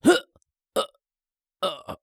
CK死亡2.wav 0:00.00 0:02.04 CK死亡2.wav WAV · 176 KB · 單聲道 (1ch) 下载文件 本站所有音效均采用 CC0 授权 ，可免费用于商业与个人项目，无需署名。
人声采集素材/男2刺客型/CK死亡2.wav